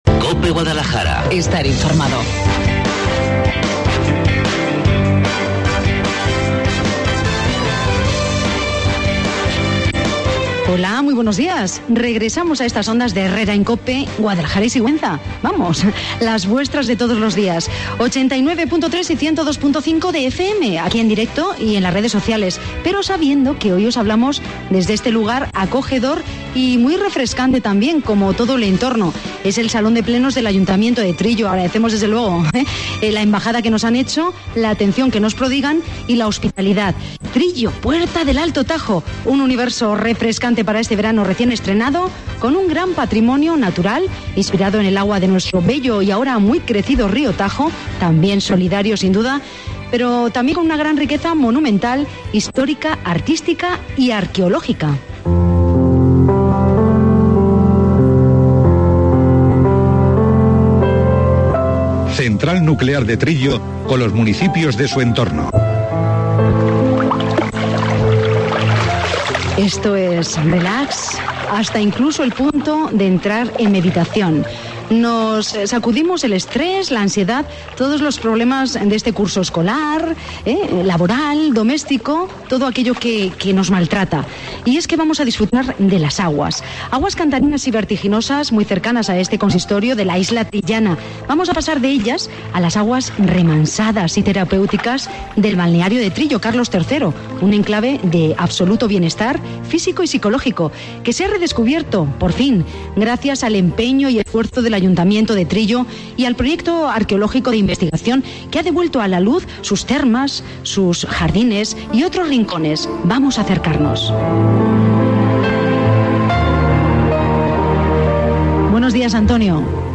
Cope Guadalajara, desde el salón de plenos de Trillo con motivo de su Feria Chica 2018
La Mañana en COPE en Guadalajara Cope Guadalajara, desde el salón de plenos de Trillo con motivo de su Feria Chica 2018 Cope Guadalajara ha viajado a Trillo y, desde el salón de Plenos de su Ayuntamiento, ha realizado en directo el programa de Herrera en Cope del 89.3 y 102.5 de FM, para conocer todos los detalles de la Feria Chica trillana 2018.